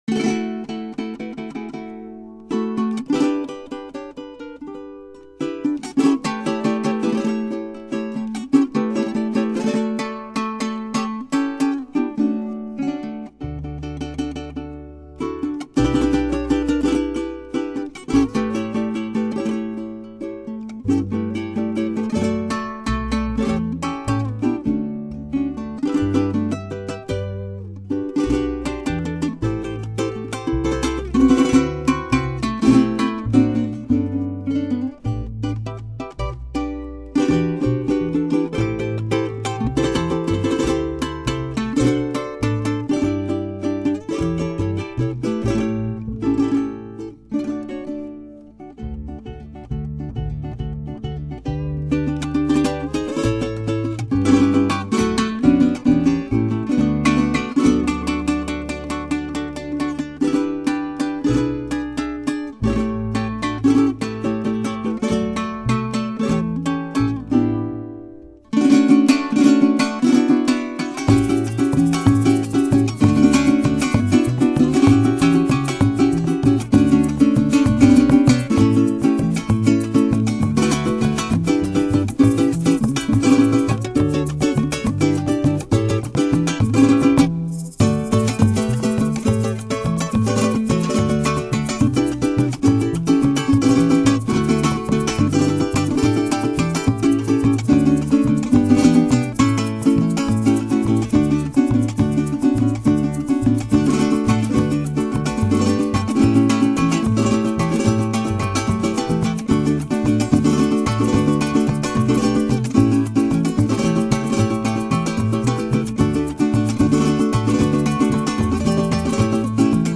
Música latina
Pars Today- La música de América Latina.